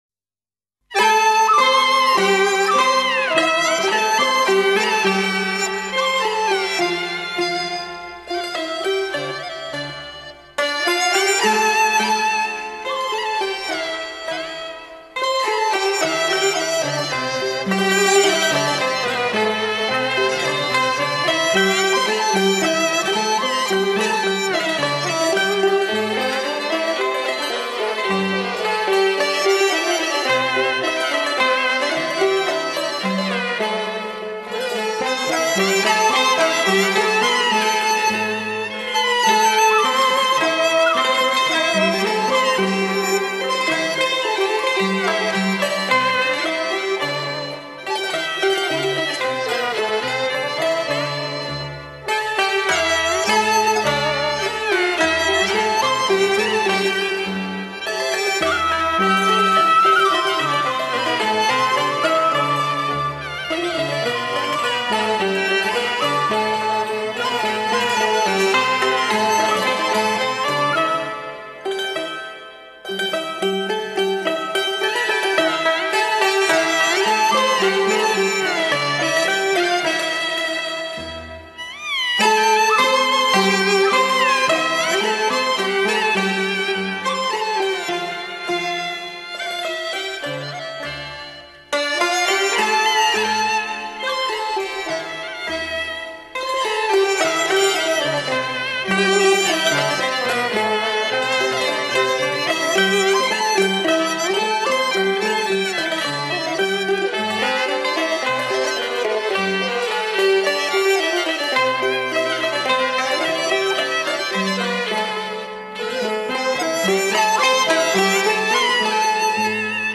人间天上 情景交融 演绎细腻
广东音乐采用正线、反线、乙反、士工等几种调，其音乐特点活泼轻快、细腻缠绵、艳郁华丽、流畅动听。